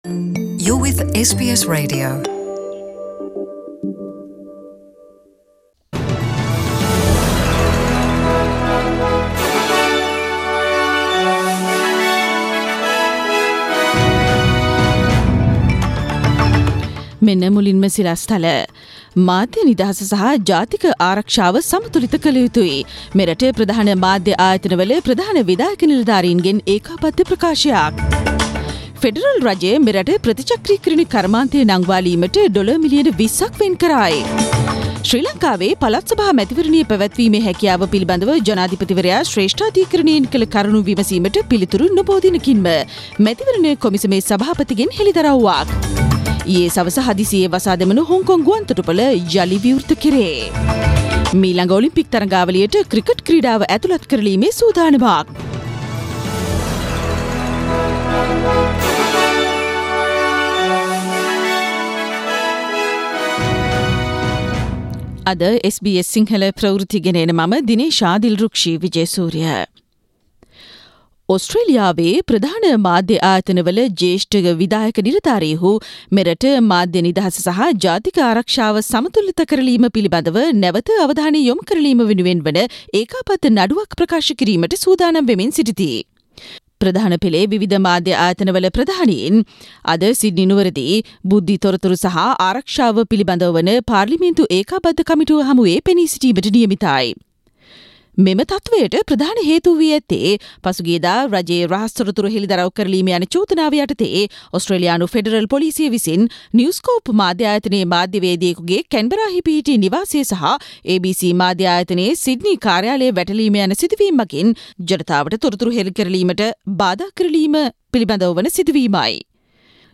අද - අගෝස්තු 13 දා Australia වේ වේලාවෙන් පෙරවරු 11 සිට 12 දක්වා විකාශනය වුනු SBS සිංහල වැඩසටහනේ දවසේ පුවත් ඉදිරිපත් කෙරුම
SBS Sinhala program Tuesday news bulletin Source: SBS Sinhala